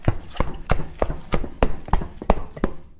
Running on Concrete
Fast-paced running footsteps on concrete pavement with rhythmic slapping impacts
running-on-concrete.mp3